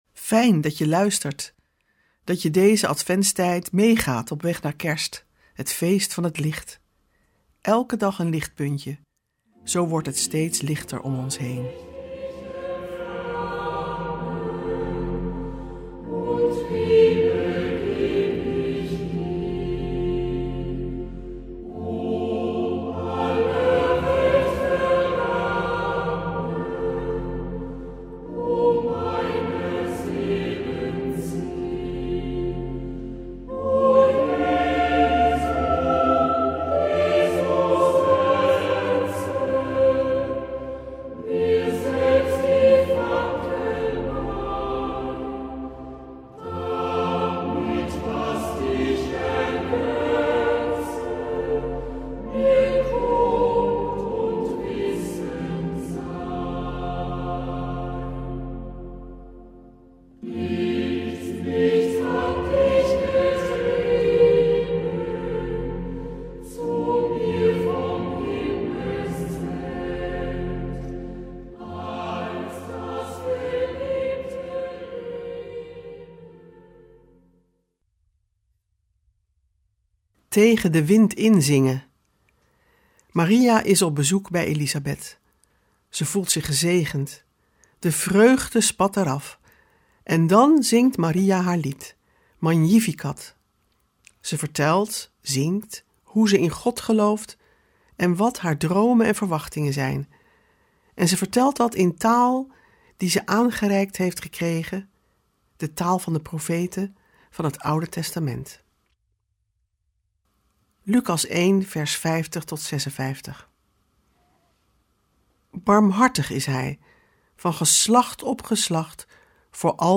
Meditaties in de Adventstijd 18 december | Radio Bloemendaal